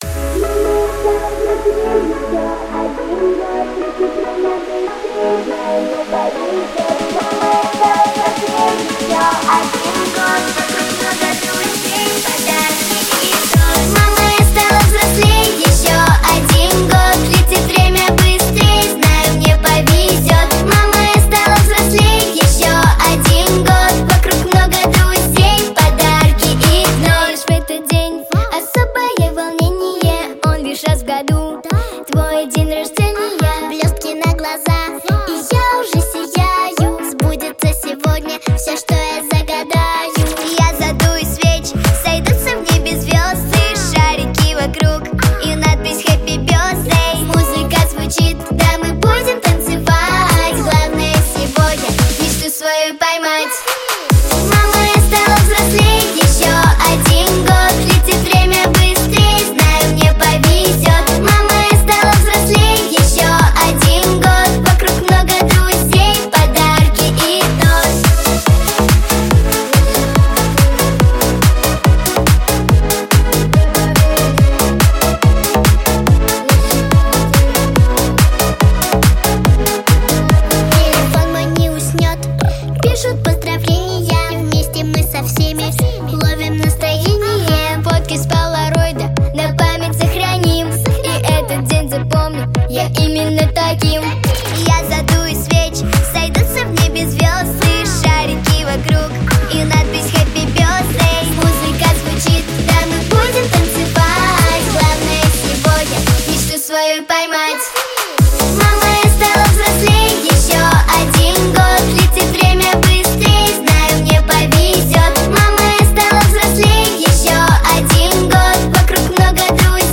ГлавнаяПесниСовременные детские песни